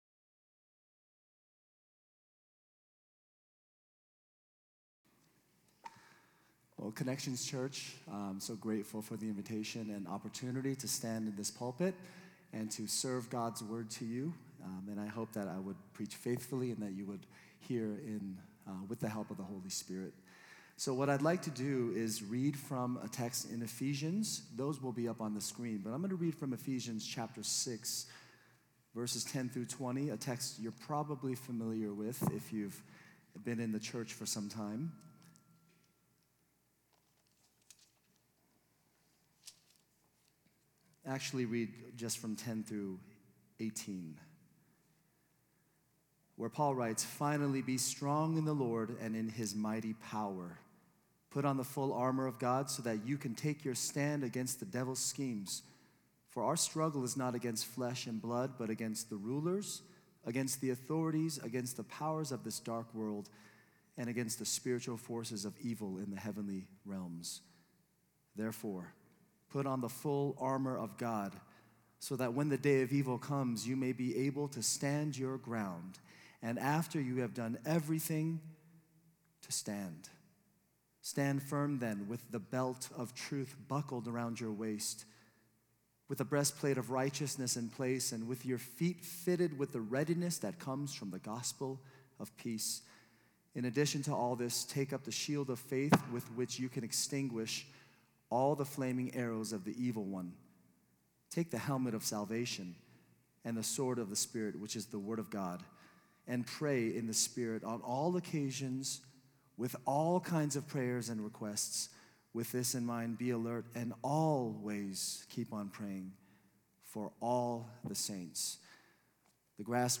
A message from the series "Sunday Services."